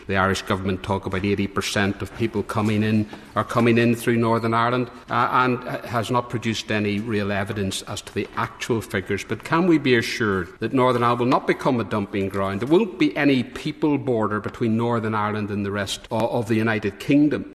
In the House of Lords last night, former DUP deputy leader, Nigel Dodds raised this concern: